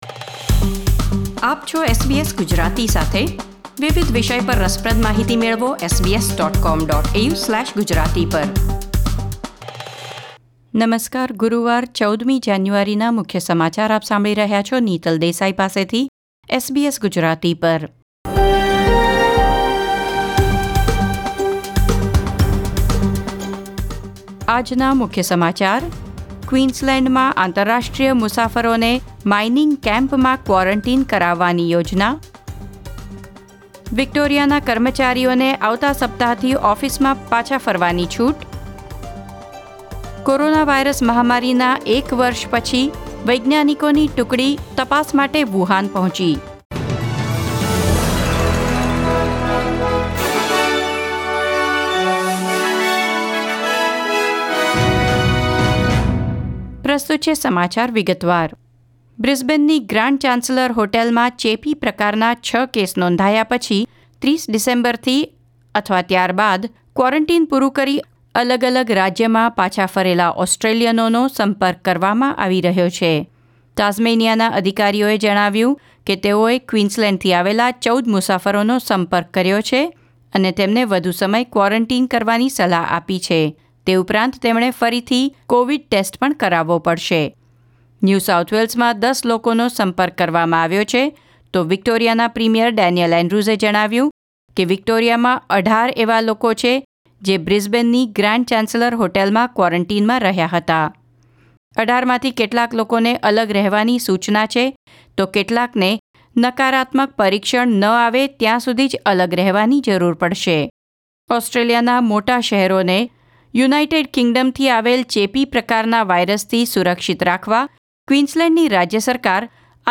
SBS Gujarati News Bulletin 14 January 2021